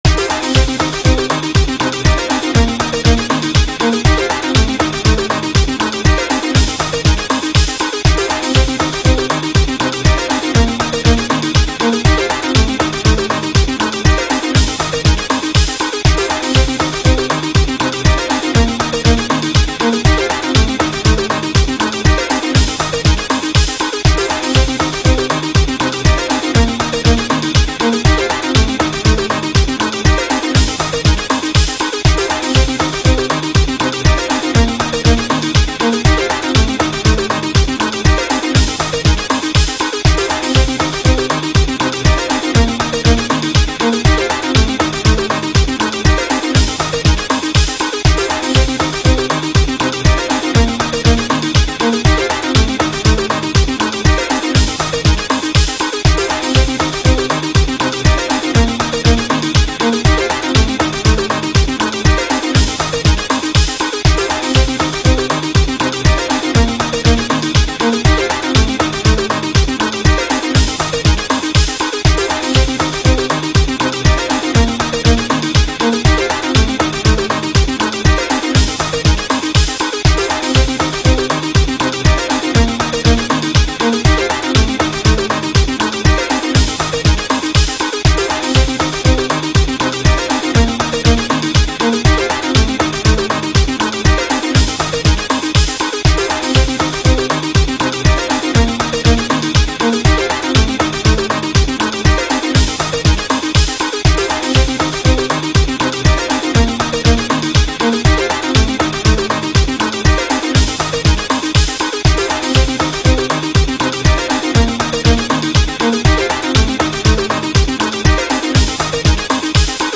I was poking around and thought that this violin track was interesting, but needed a little more dancability to it.
I paired it up with 3 tracks of beats, and then to cater to the bass that was missing, I added a grumpy but bright synthesizer that would make it stompy-danceable.
It goes on for a little song, but it sounds like a sketch of a song I haven't done tweaking on.
Surprisingly vaguely danceable!
grumpyviolas13542.m4a